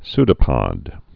(sdə-pŏd)